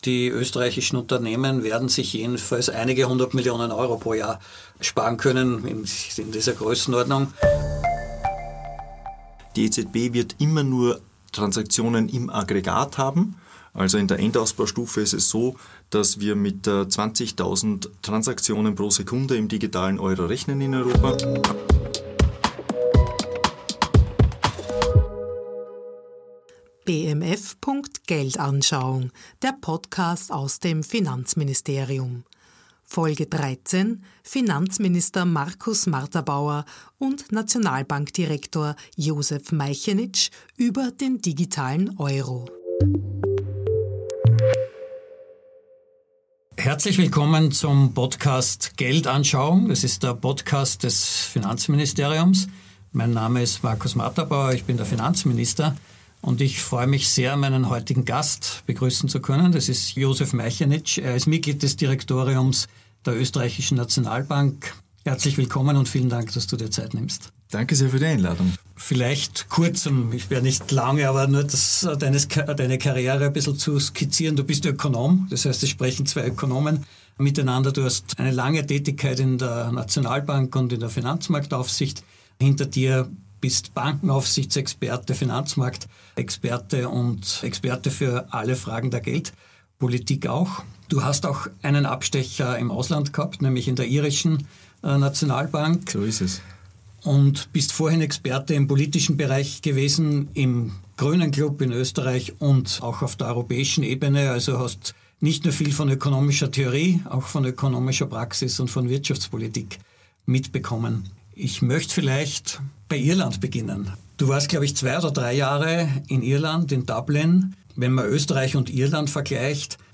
#13 Finanzminister Markus Marterbauer und OeNB-Direktor Josef Meichenitsch über den digitalen Euro ~ BMF.Geldanschauung Podcast